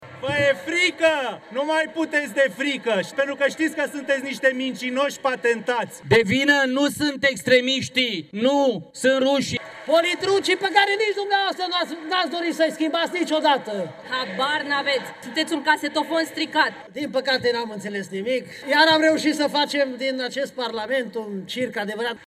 Totul – în fața unui grup de copii care asistau la ședința din Parlament.
Luările de cuvânt au fost pe fondul huiduielilor din partea Opoziției.